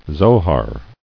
[Zo·har]